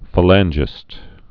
(fə-lănjĭst, fālăn-)